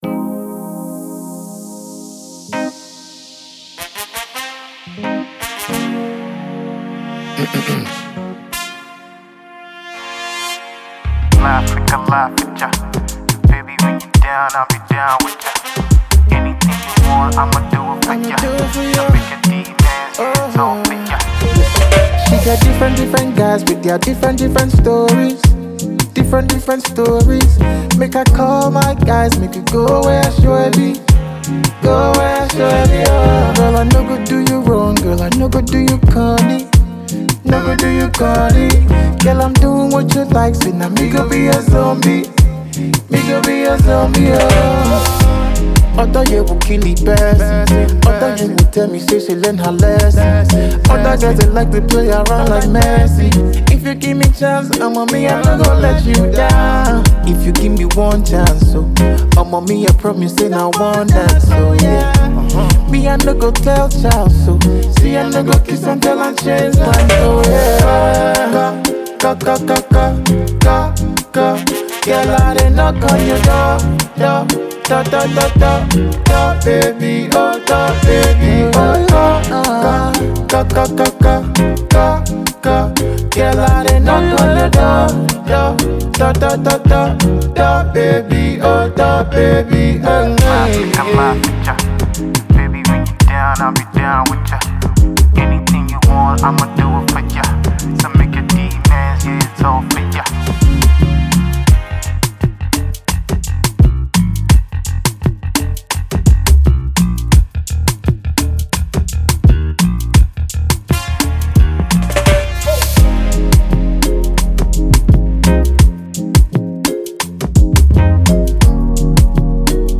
soft melodious record